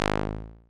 rubberband.wav